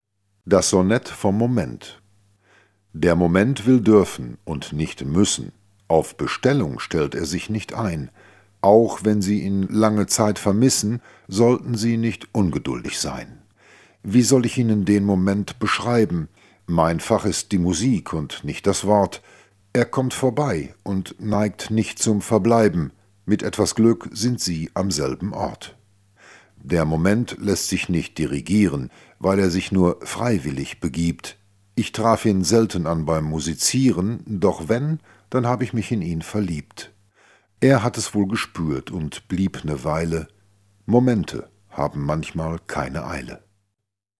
Gelesen von Fritz Eckenga.